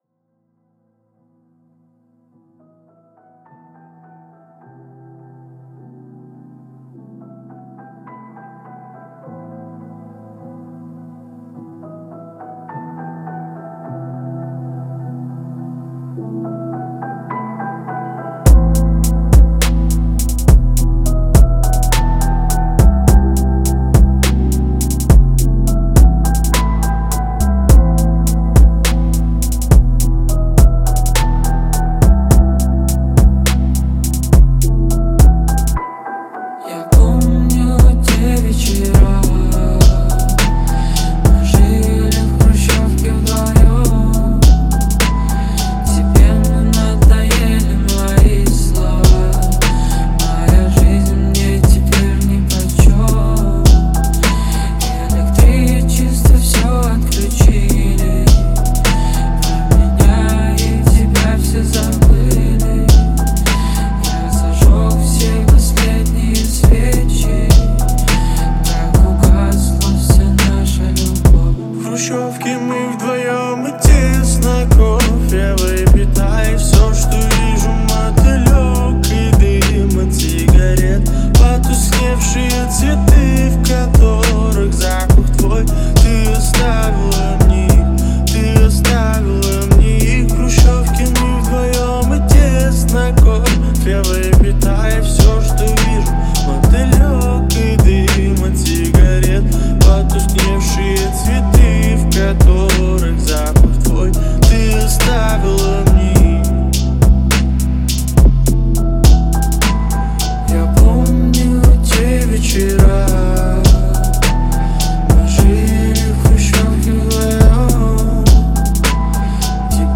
это динамичный трек в жанре поп и EDM
с современными электронными ритмами